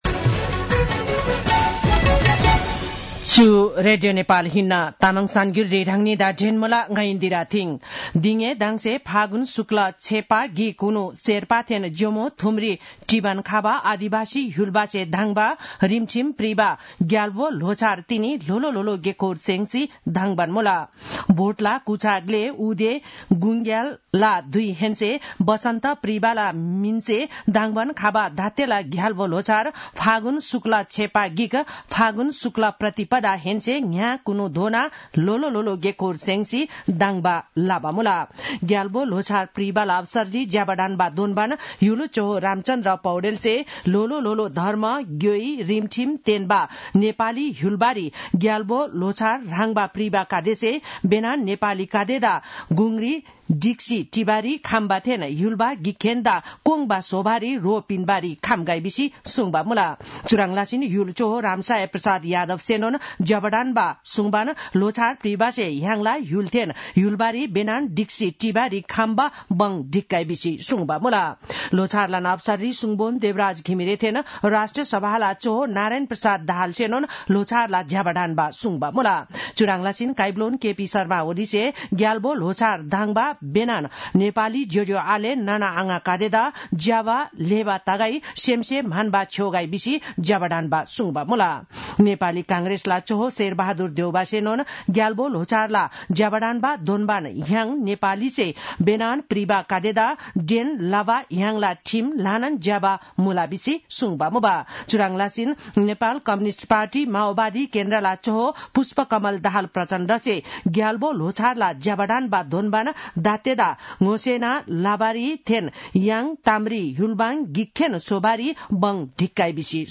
तामाङ भाषाको समाचार : १७ फागुन , २०८१
Tamang-news-11-16.mp3